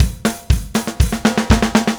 Power Pop Punk Drums 01 Fill A.wav